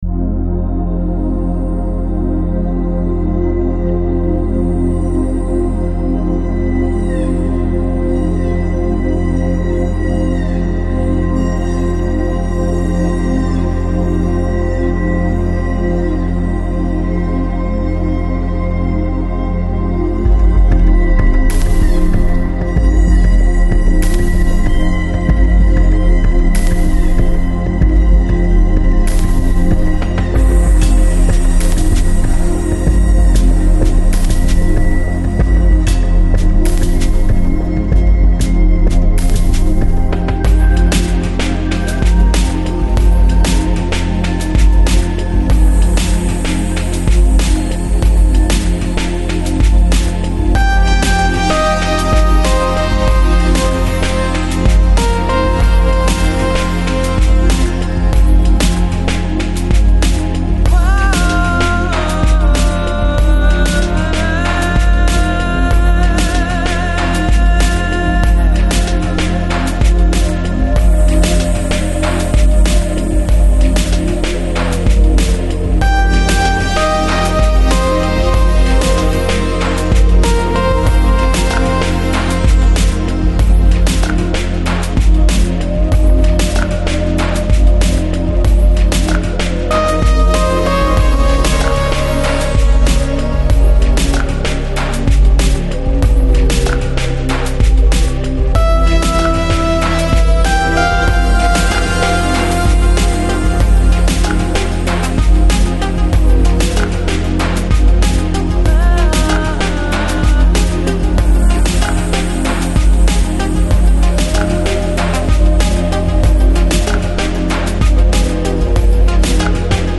Electronic, Lounge, Chill Out, Enigmatic